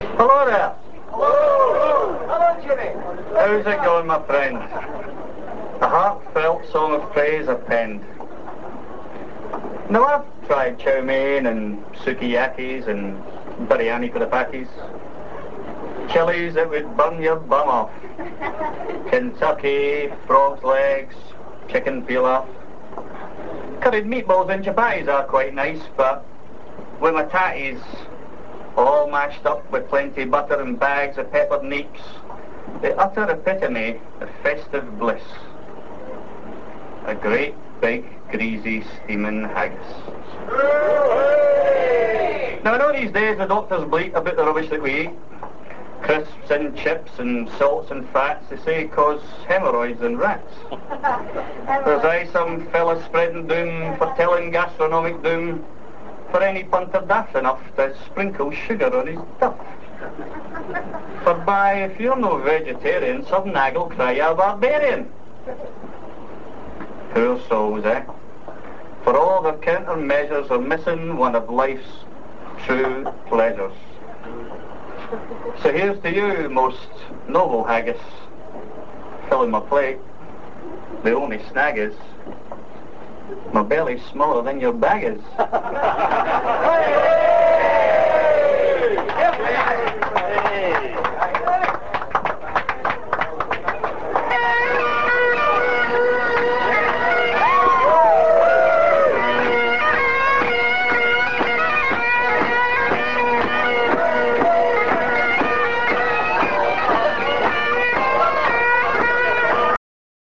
London Hibs Annual Burns Night Supper was held on Saturday 22nd January 2000 at the Kavanagh's Pub, Old Brompton Road.
haggis_address.rm